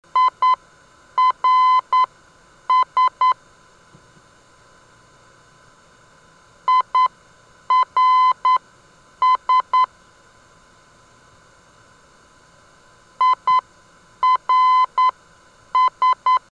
IRS - Sturgis heard on 382 kHz: (259 kb)